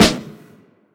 Snares
DA_SNR (3).wav